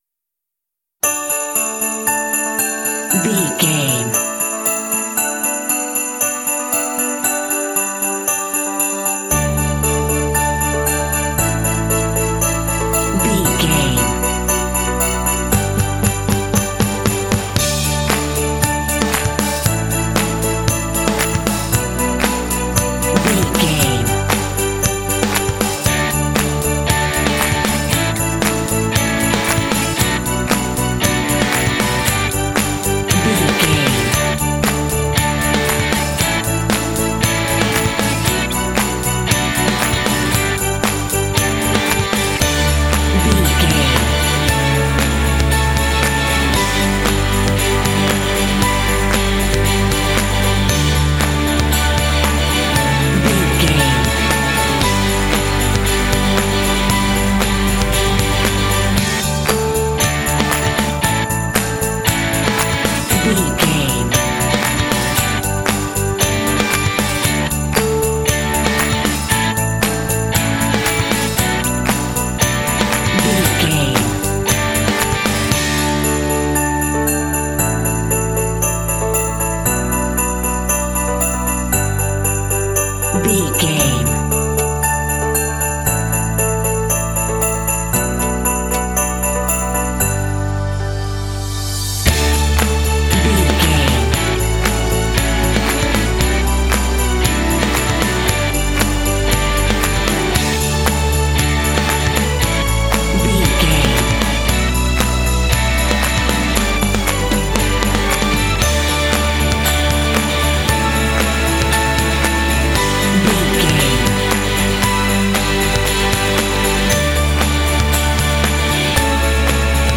Ionian/Major
happy
uplifting
bouncy
festive
piano
strings
bass guitar
electric guitar
contemporary underscore